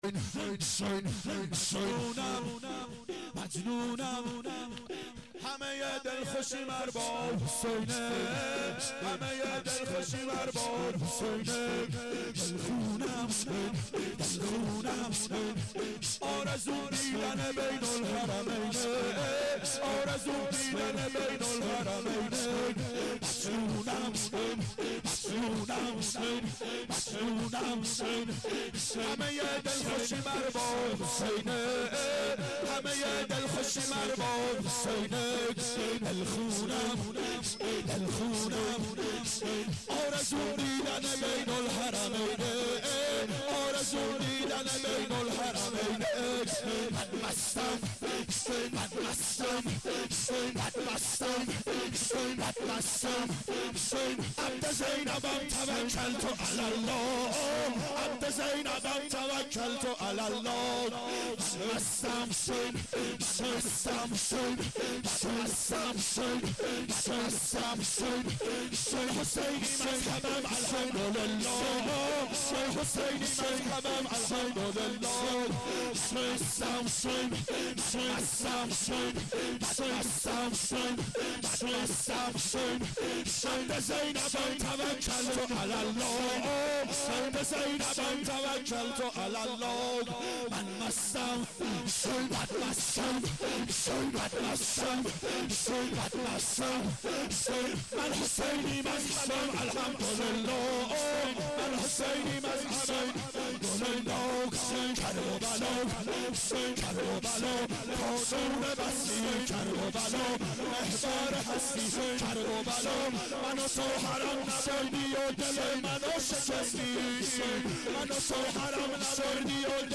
اربعین 91 هیئت متوسلین به امیرالمؤمنین حضرت علی علیه السلام